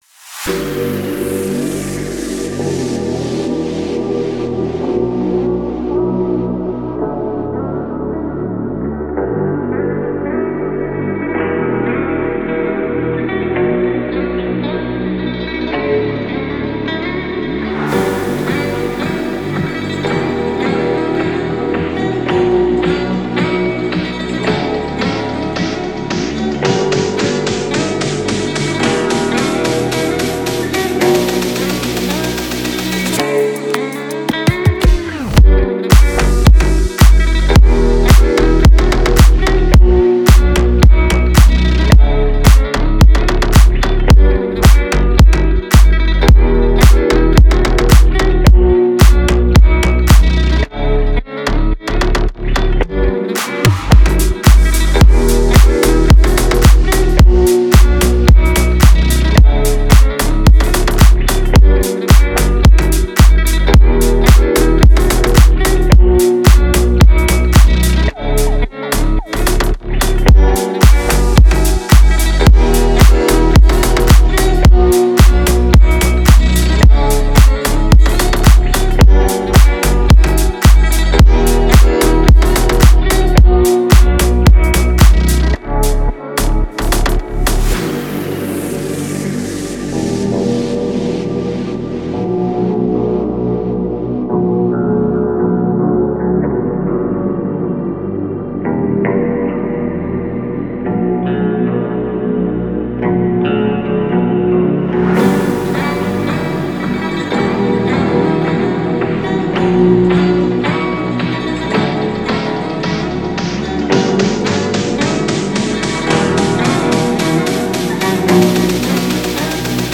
яркая и мелодичная песня